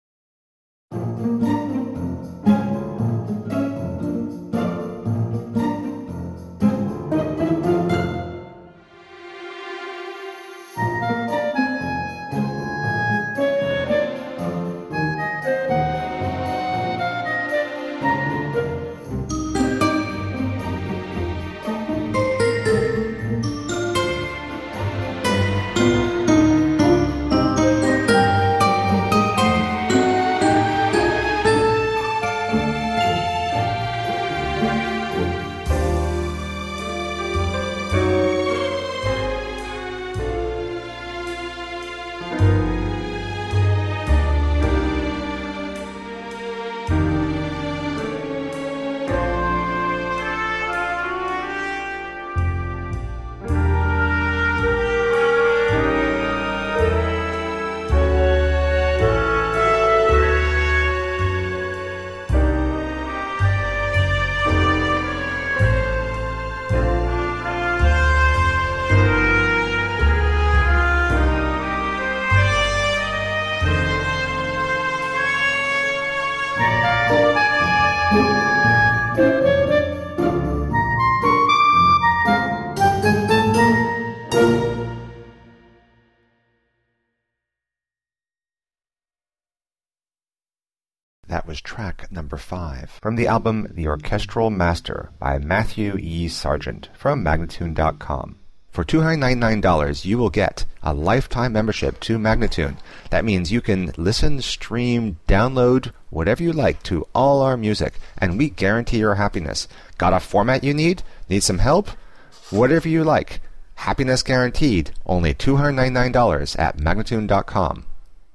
Masterfully composed orchestral and electronic film scores.